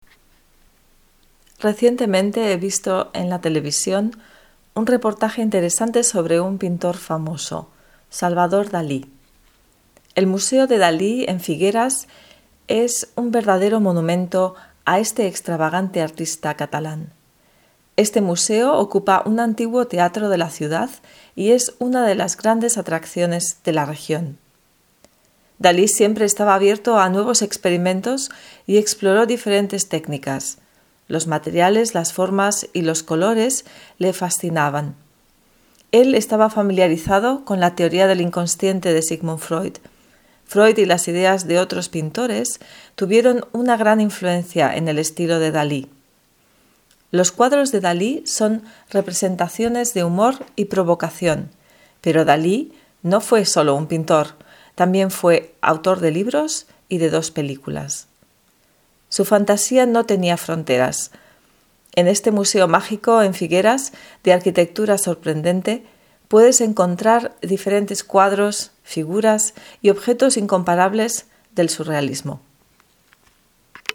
READING-on-DALI.mp3